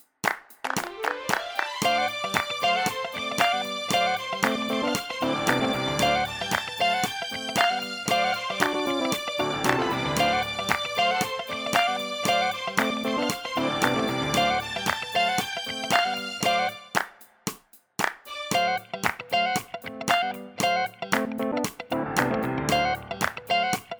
no kit bass or main guitar Disco 3:41 Buy £1.50